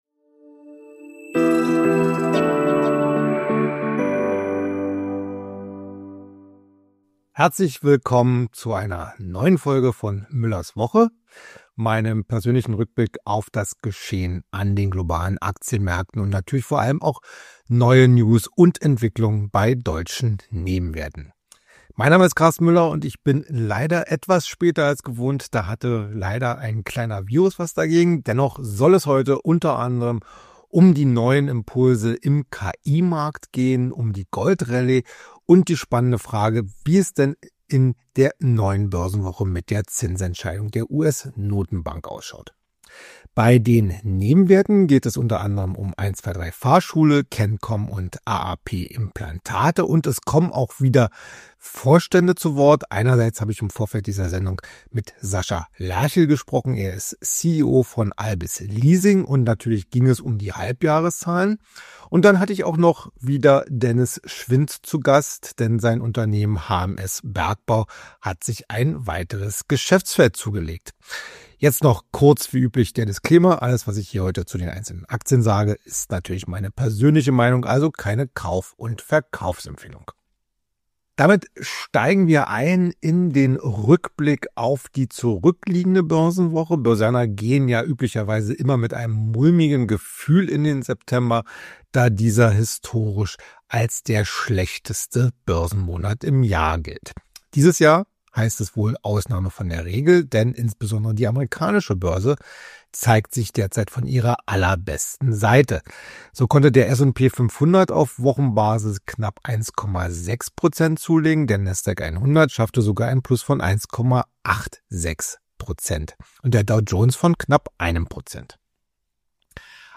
Besonders im Fokus stehen zwei Kurz-Interviews